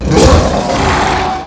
c_alien_atk1.wav